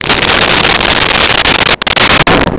Sfx Amb Scrape Metal 01
sfx_amb_scrape_metal_01.wav